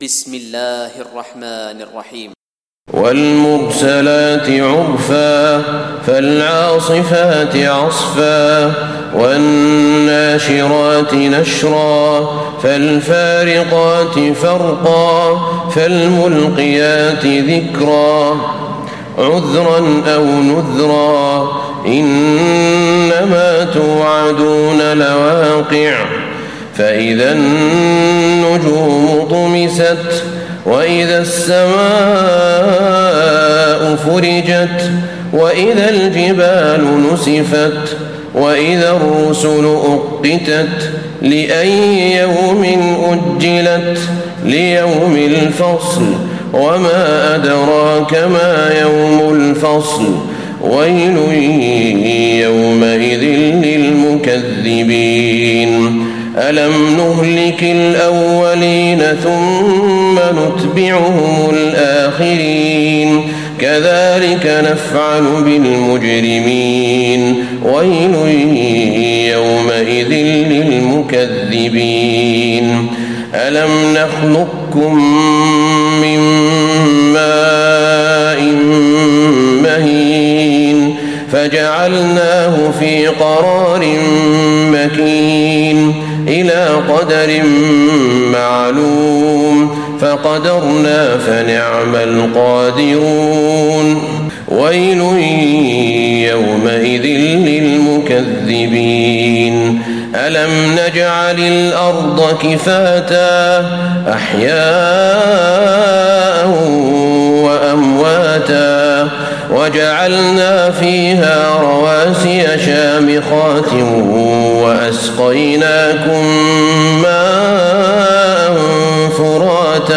تراويح ليلة 28 رمضان 1436هـ سورة المرسلات Taraweeh 28 st night Ramadan 1436H from Surah Al-Mursalaat > تراويح الحرم النبوي عام 1436 🕌 > التراويح - تلاوات الحرمين